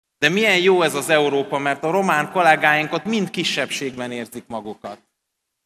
„De milyen jó ez az Európa, mert a román kollégák ott mind kisebbségben érzik magukat”. Ez volt Sógor Csaba székelyudvarhelyi beszédének az a mondata, amely heves reakciókat váltott ki a hét végén a román politikusokból.